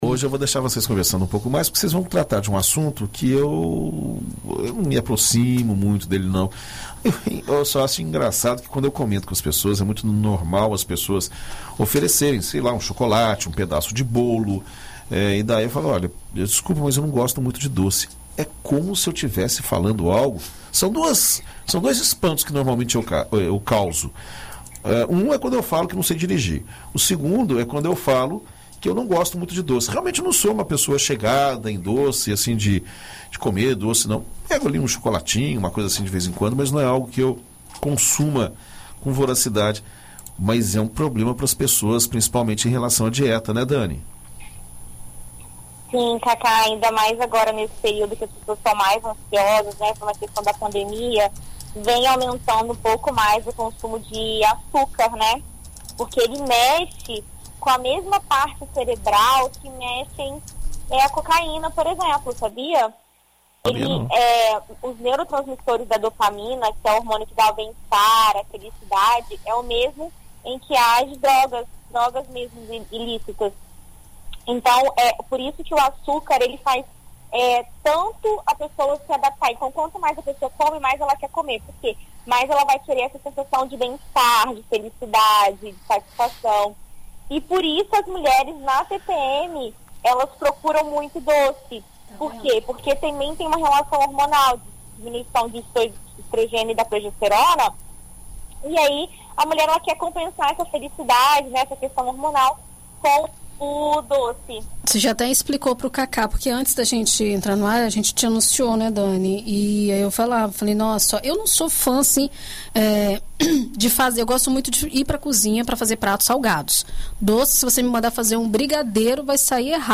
Na coluna Viver Bem desta quarta-feira (02), na BandNews FM Espírito Santo